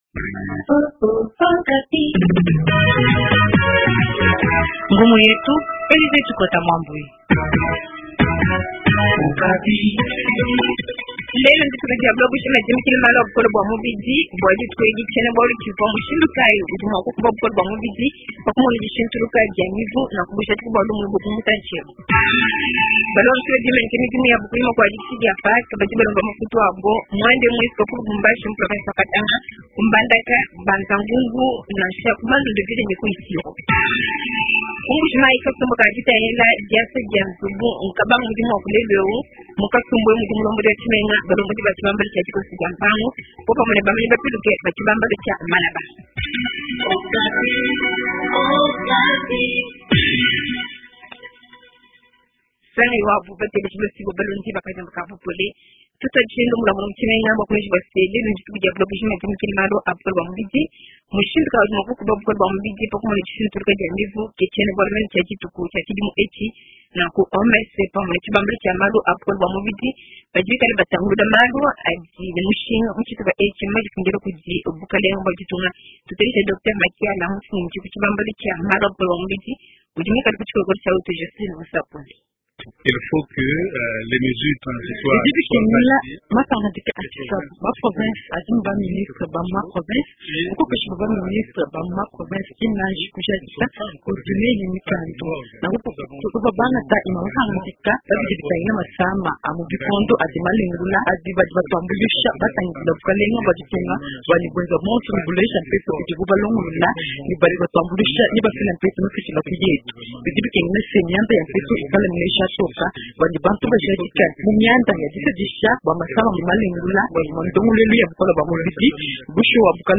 Journal Tshiluba Soir